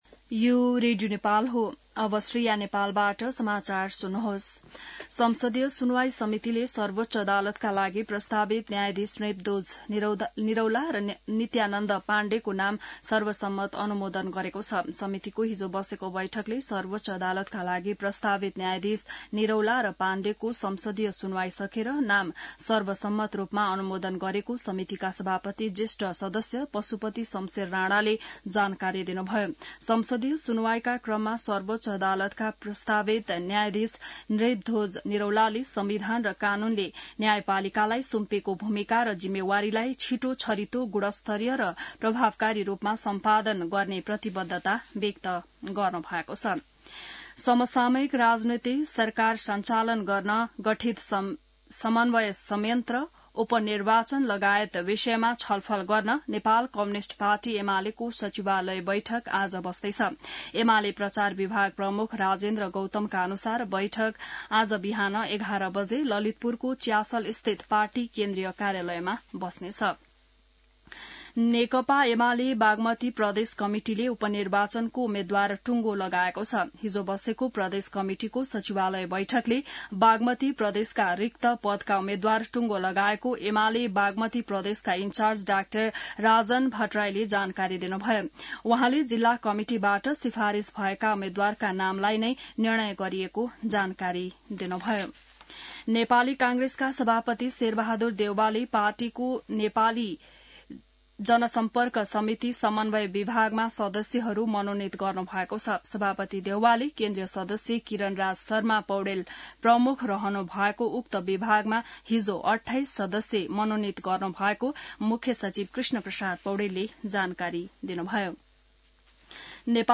बिहान ६ बजेको नेपाली समाचार : २५ कार्तिक , २०८१